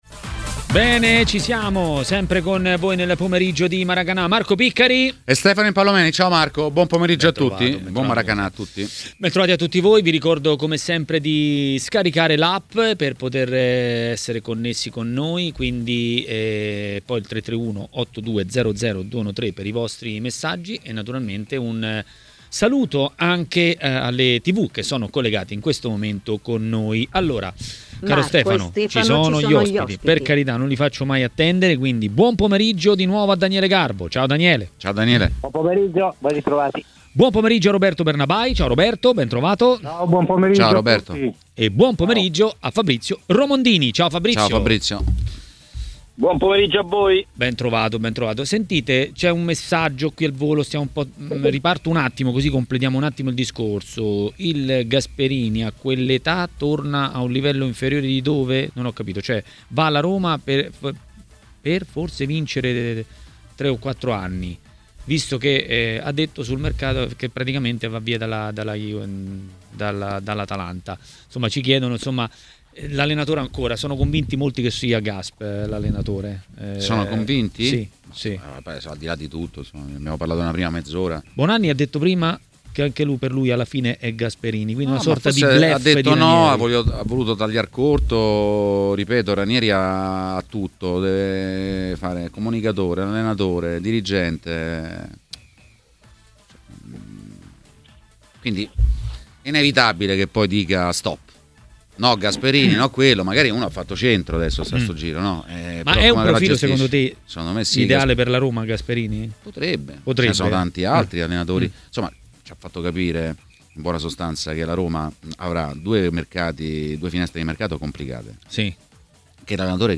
Ospite di Maracanà, nel pomeriggio di TMW Radio